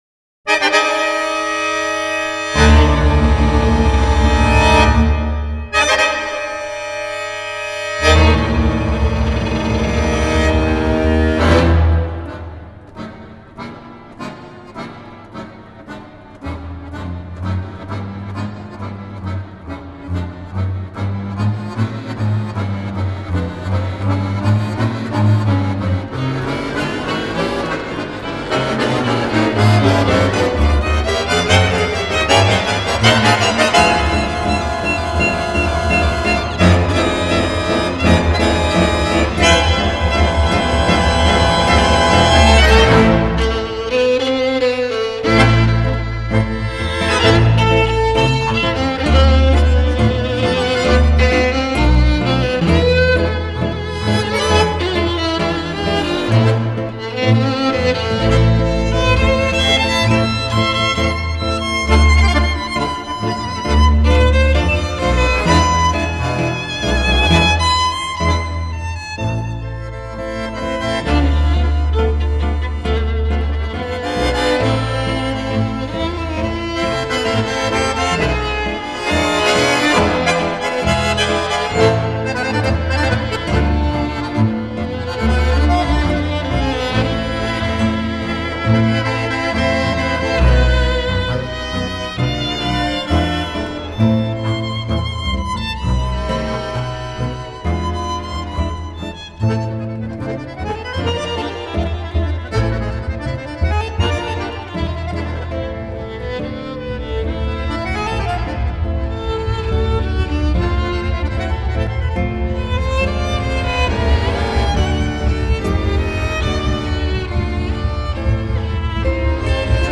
который состоял из баяна, скрипки, гитары и контрабаса.